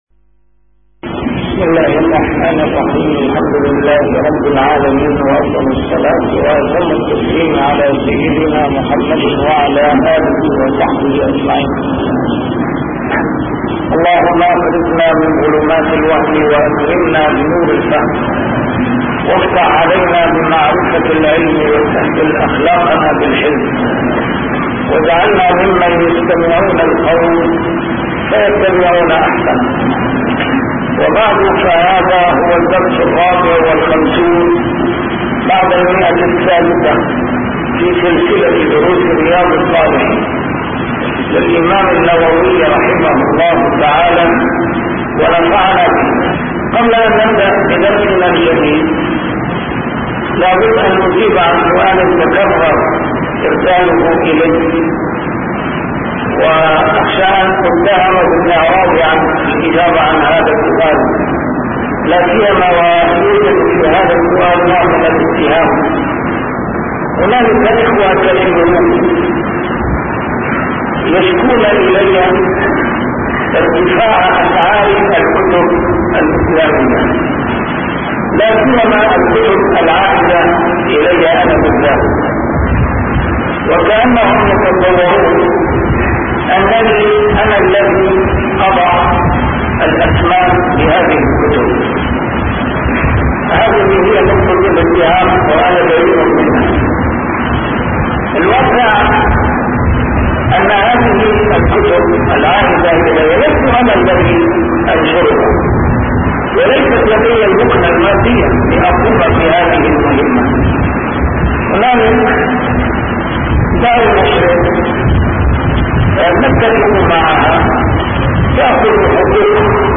A MARTYR SCHOLAR: IMAM MUHAMMAD SAEED RAMADAN AL-BOUTI - الدروس العلمية - شرح كتاب رياض الصالحين - 654- شرح رياض الصالحين: الشرب من الأواني الطاهرة